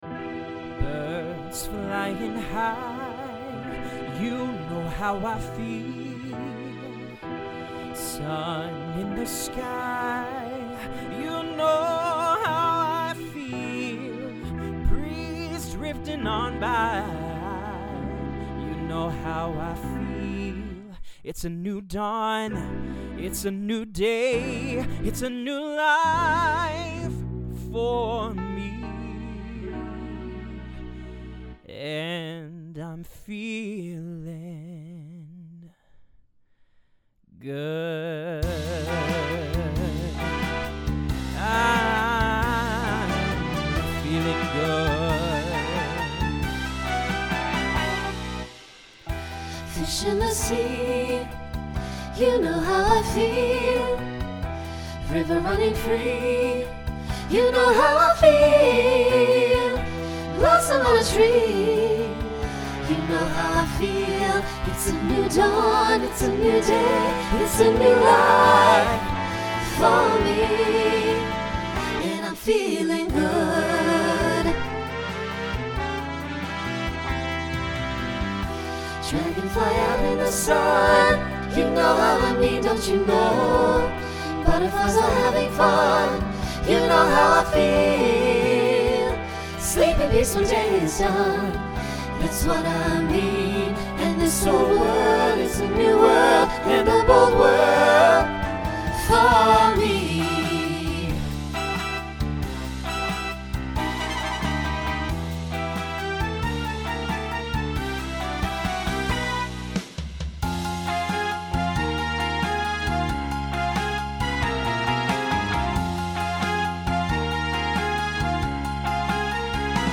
Voicing SATB Instrumental combo
Broadway/Film , Swing/Jazz
Mid-tempo